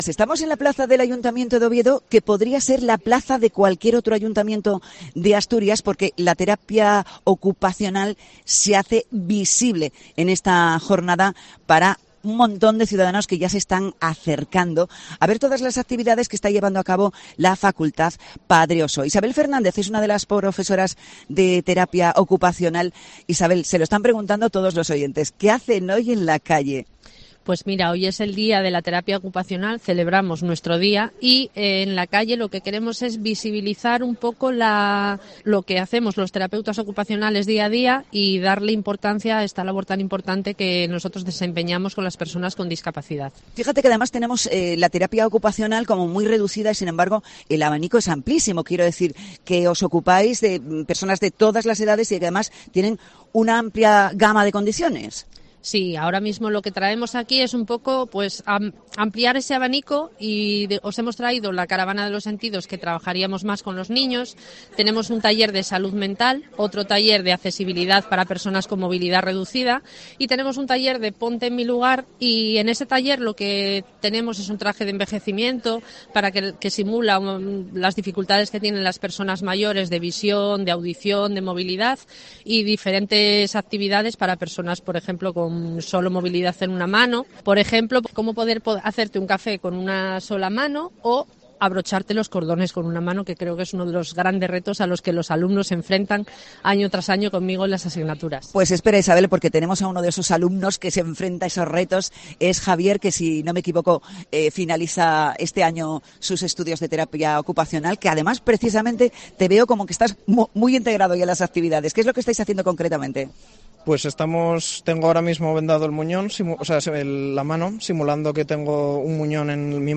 Programa Especial sobre Terapia Ocupacional